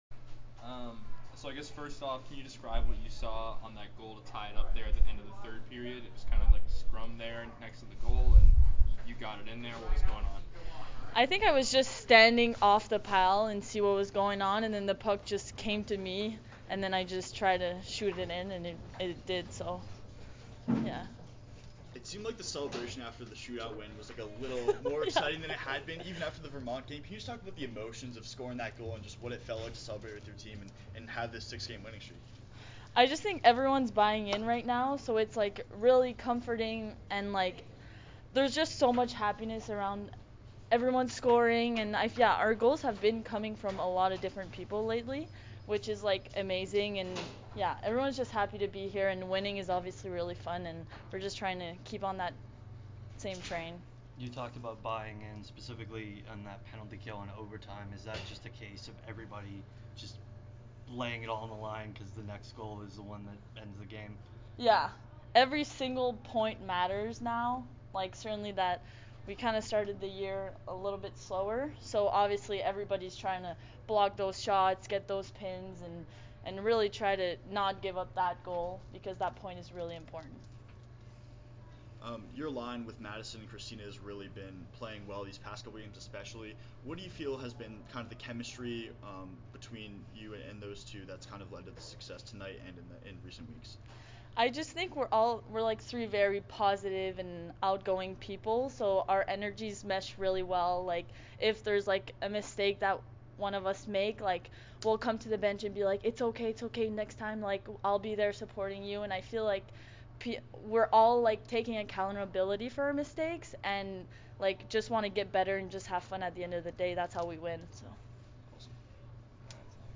Women's Ice Hockey / UConn Postgame Interview (2-3-23)